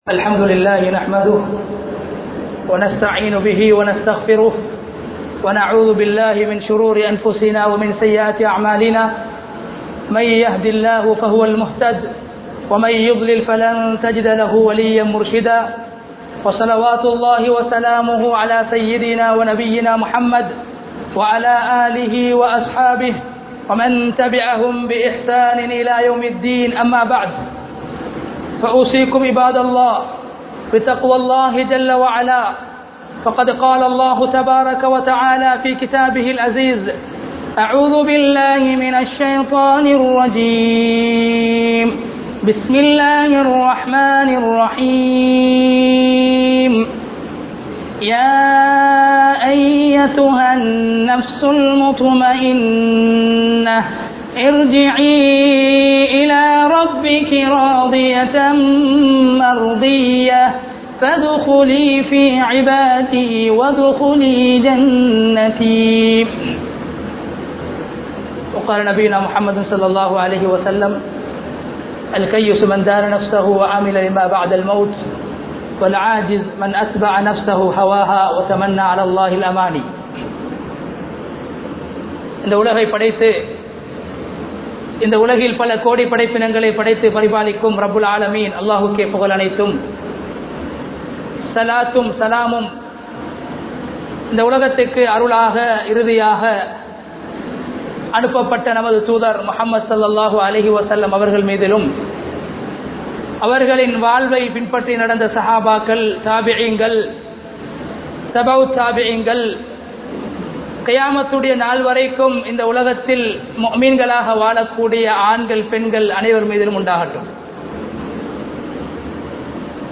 Noanpum Nafsum (நோன்பும் நப்ஸூம்) | Audio Bayans | All Ceylon Muslim Youth Community | Addalaichenai
Jumua Masjidh